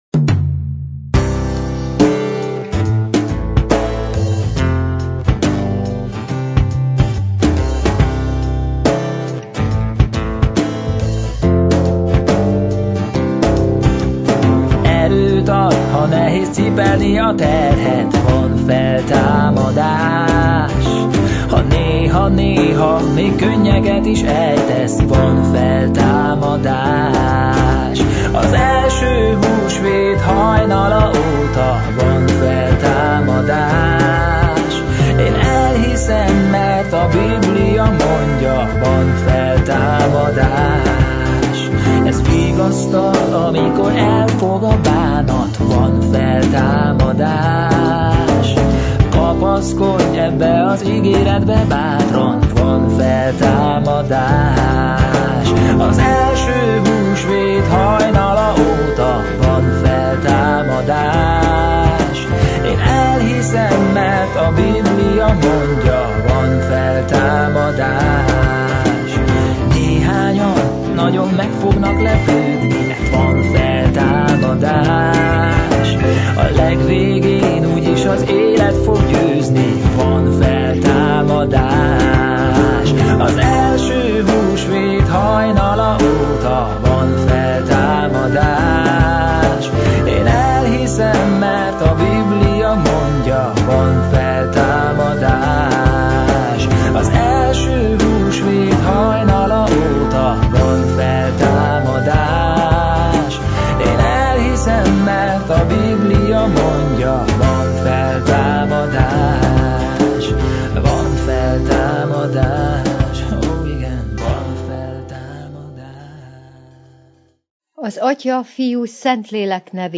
Igét hirdet